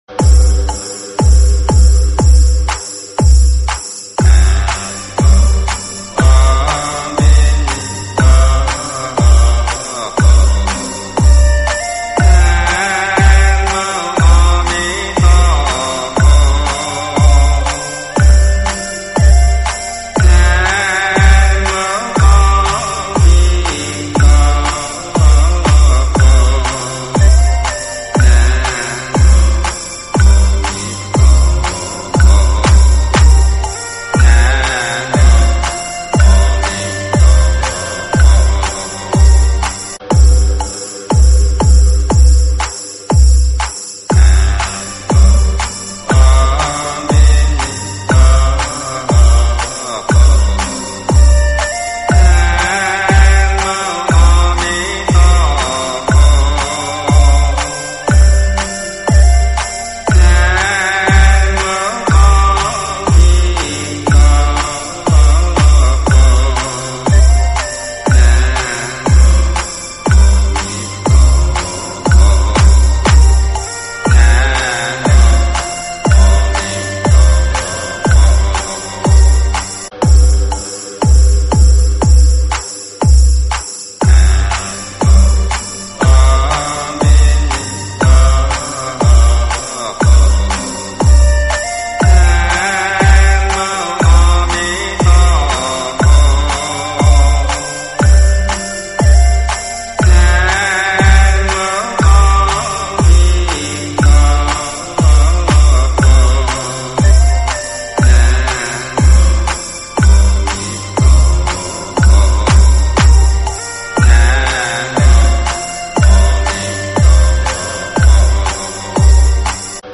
[20/8/2009]佛音--和尚念阿弥陀佛经慢摇